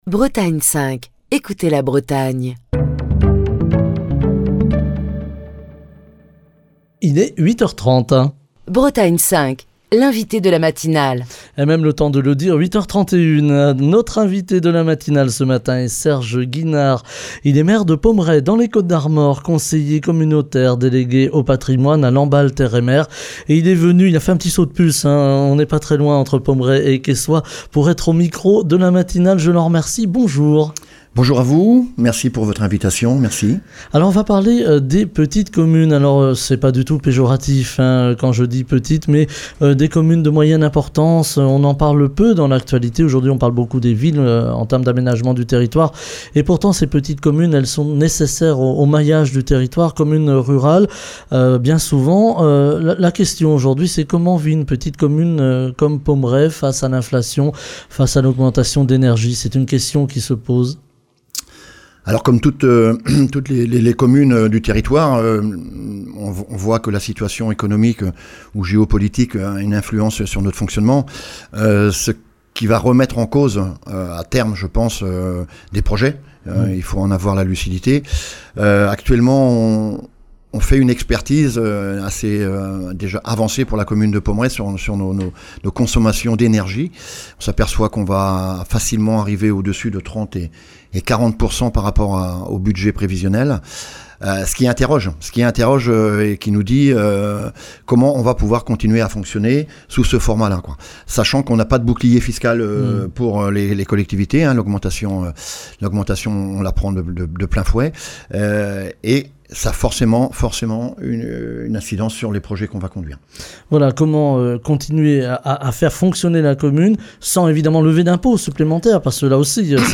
Les communes rurales maillons essentiel du territoire font face à de nombreux défis en matière de logement, de gestion des finances publiques en ces temps de crise et de développement. Ce matin, nous évoquons la commune de Pommeret dans les Côtes-d'Armor, avec Serge Guinard son maire, également conseiller communautaire délégué au patrimoine à Lamballe Terre et Mer et qui est l'invité de la matinale de Bretagne 5.